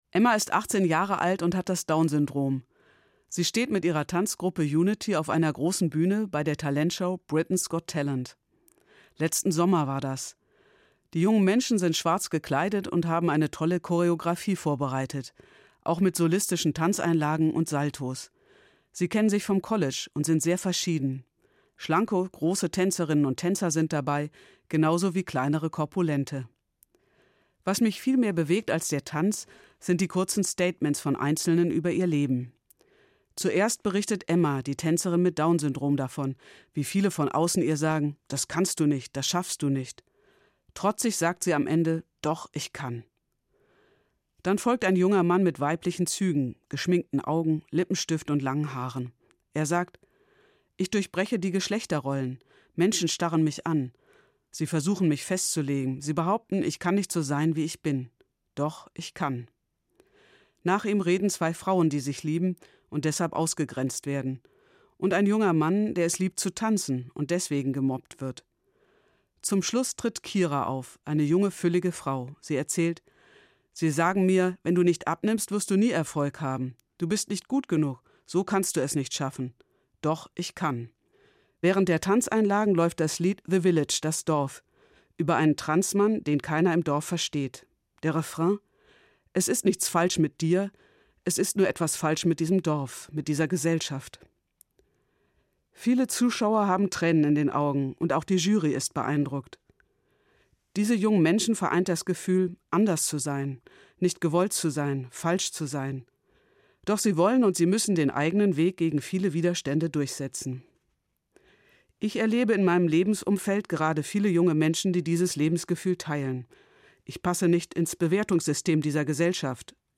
Evangelische Pfarrerin, Frankfurt